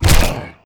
Damage6.ogg